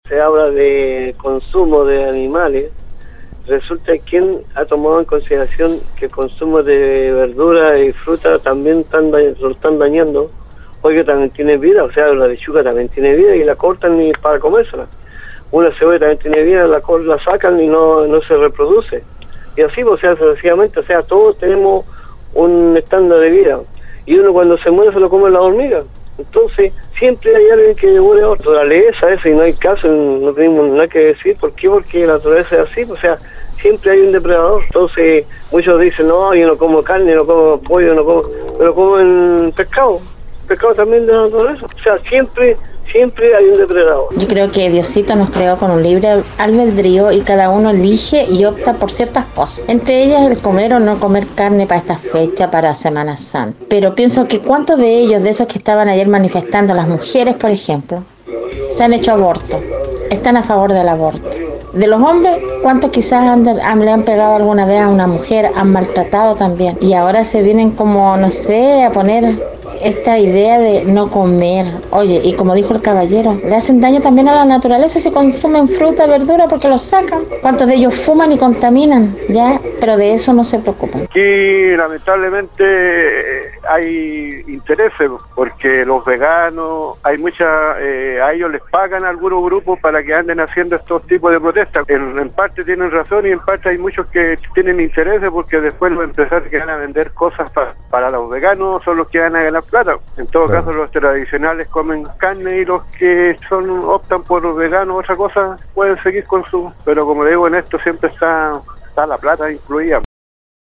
La mañana de este lunes, auditores de Nostálgica participaron en el foro del programa Al Día en relación a una actividad que se realizó este domingo donde más de 50 activistas de Resiste Animal y otras organizaciones de pro animalistas realizaron en Valparaíso una intervención artística  y cultural donde mostraron al público lo que deben sufrir las especies para luego terminar en un plato.